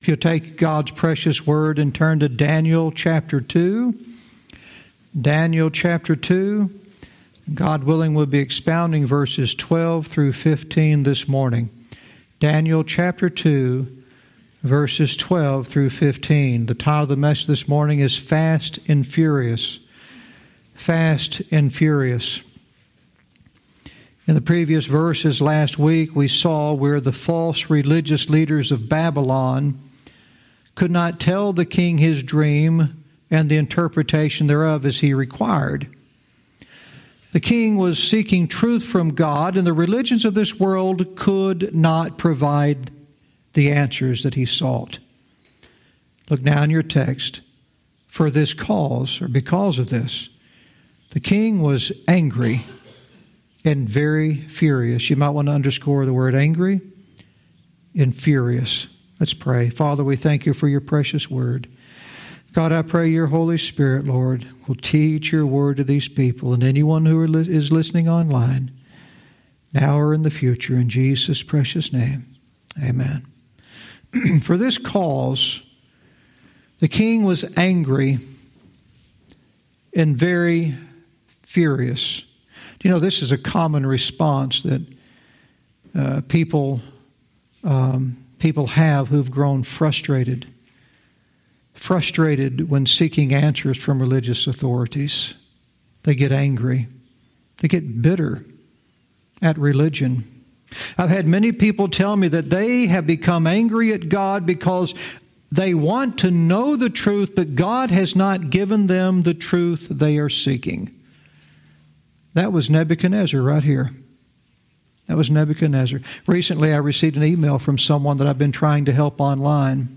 Verse by verse teaching - Daniel 2:12-15 "Fast and Furious"